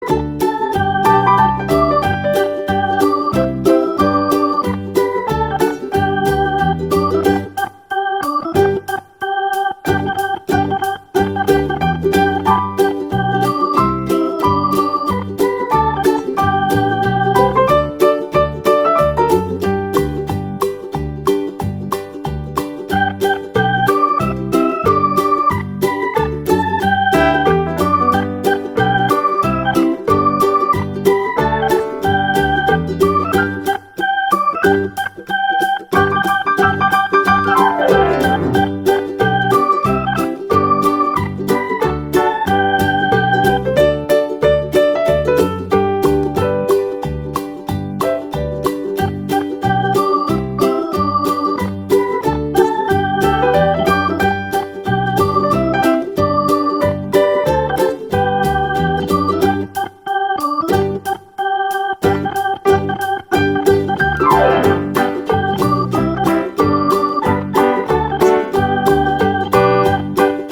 KGqbO2pUoGv_musica-infantil-para-fiestas-familiares-instrumental-hwiRCTZG.mp3